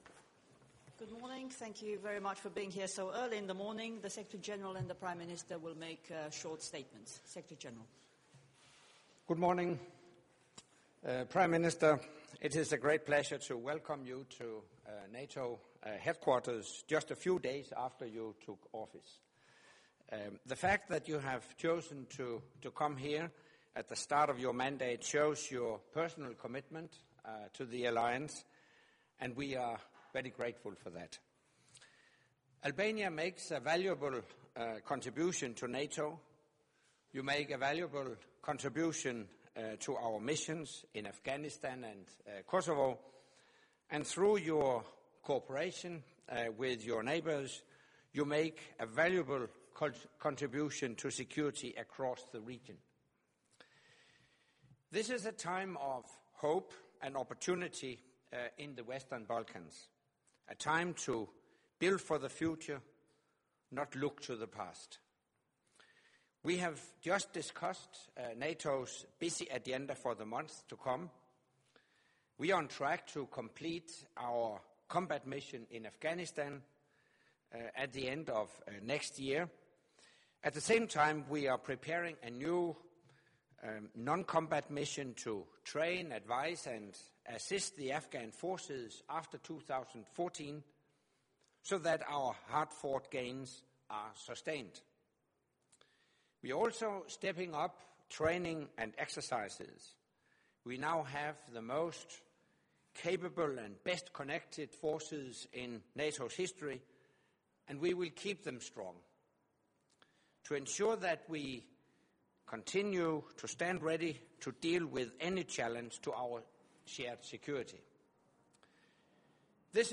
Joint press point with NATO Secretary General Anders Fogh Rasmussen and the Prime Minister of Albania, Mr. Edi Rama